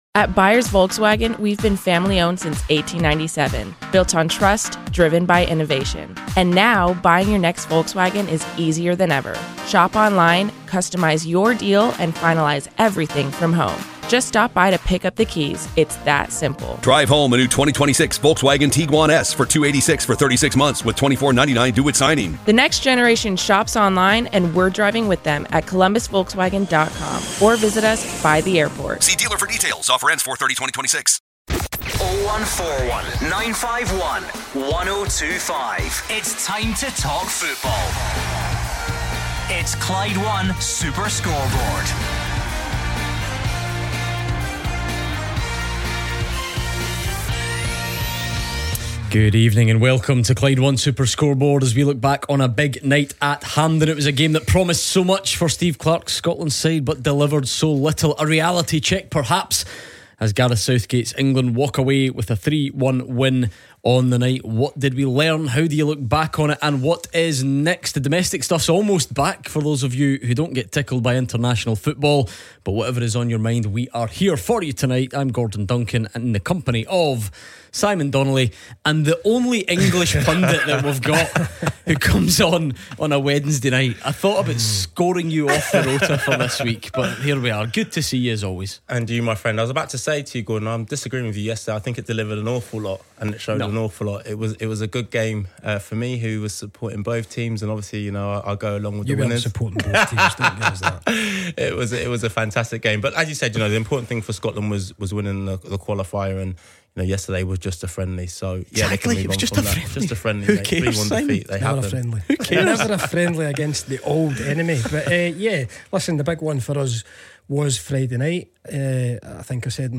The title race continues to take focus with Celtic and Rangers fans making their opinions known on the destination of the trophy. Elsewhere, on the international front, Scotland assistant Steven Naismith is sure underappreciated boss Steve Clarke's future will soon be resolved and Nathan Patterson is determined to make the World Cup squad after missing the Euros. Meanwhile, our Partick Thistle duo react to closing the gap on Championship leaders St Johnstone to five points with a win over Raith Rovers on Tuesday, with Jags fans giving their views on the phones.